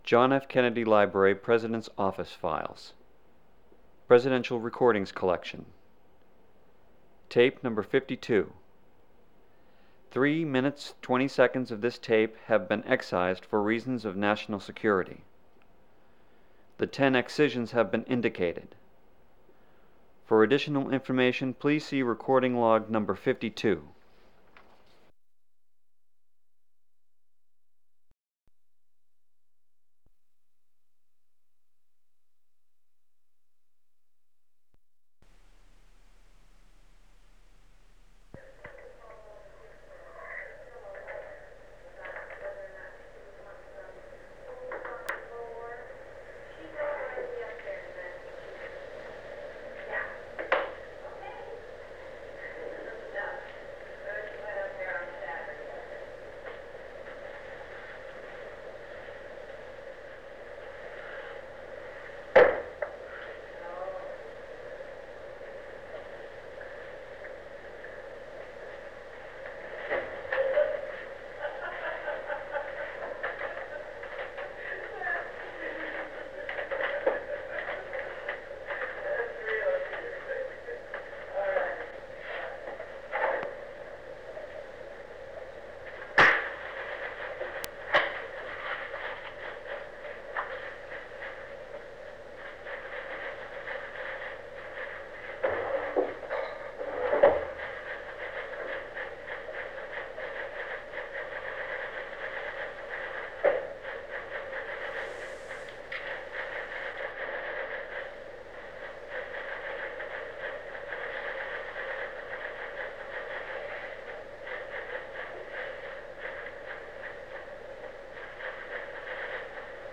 Conversations on Report of Surveillance Plane Incident
Secret White House Tapes | John F. Kennedy Presidency Conversations on Report of Surveillance Plane Incident Rewind 10 seconds Play/Pause Fast-forward 10 seconds 0:00 Download audio Previous Meetings: Tape 121/A57.